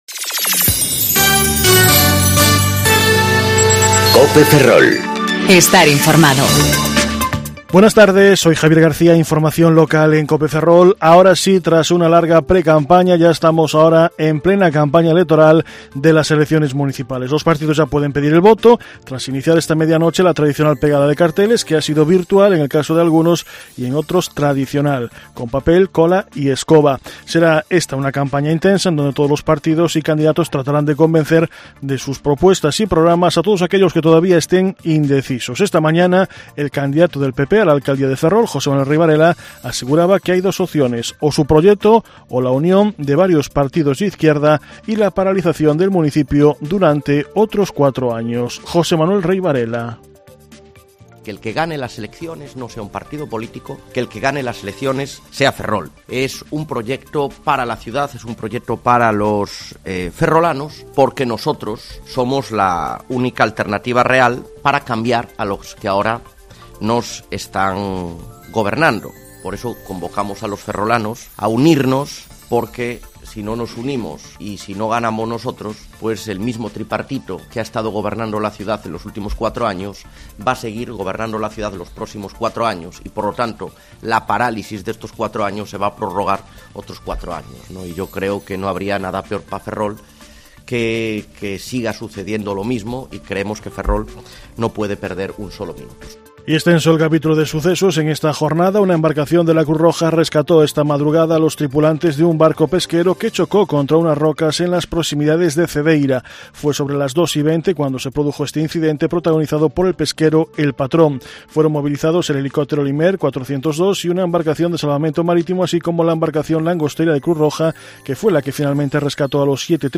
Informativo Mediodía Cope Ferrol 10/05/2019 (De 14.20 a 14.30 horas)